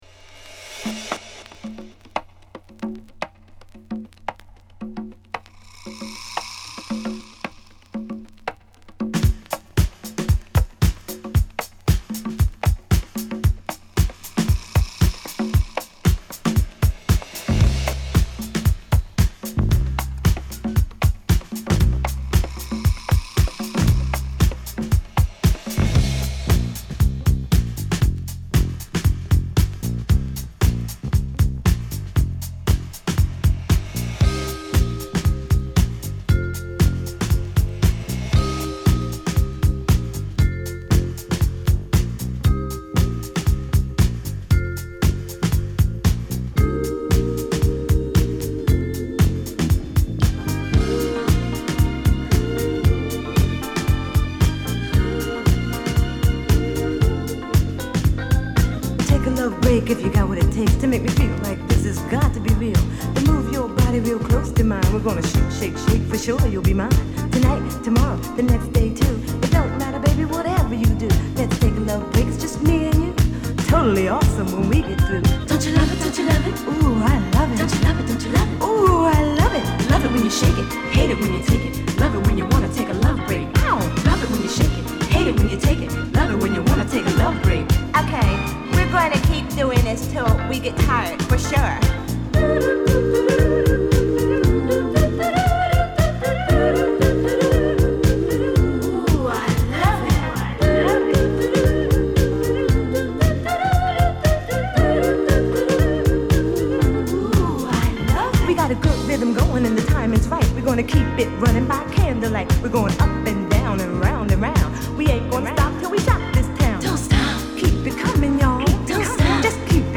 オールドスクールラップ風のヴォーカルも乗せたディスコチューン！